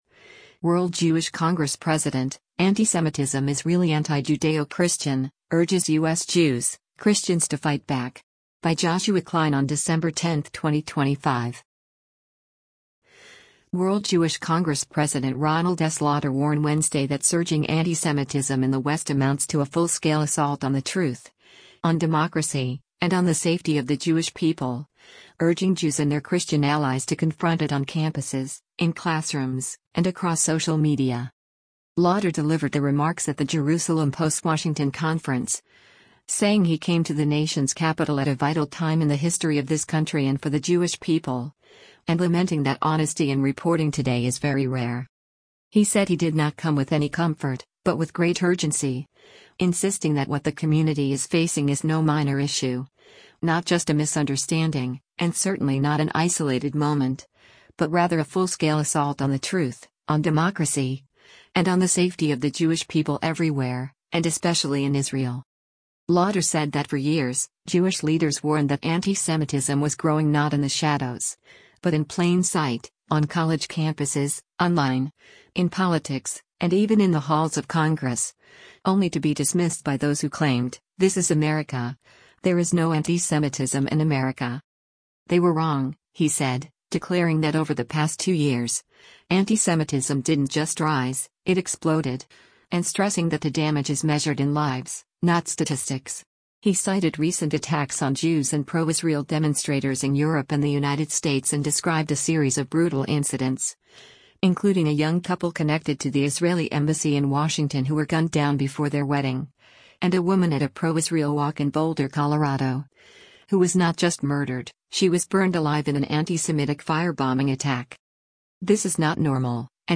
Lauder delivered the remarks at the Jerusalem Post Washington Conference, saying he came to the nation’s capital “at a vital time in the history of this country and for the Jewish people,” and lamenting that “honesty in reporting today is very rare.”